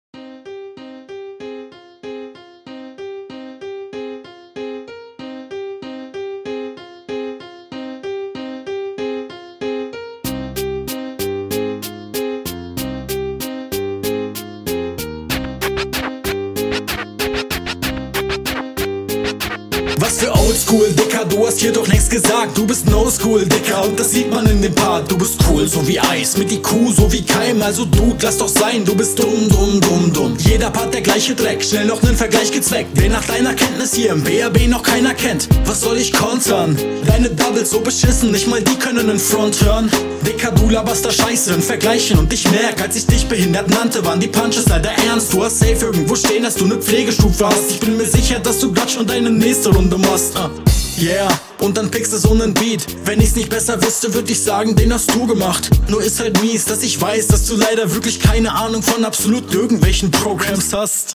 Richtig in Battle Laune, nice.
Super Konter, alles zerlegt, besserer Flow, bessere Reime, bessere Soundqualität eeeeasy win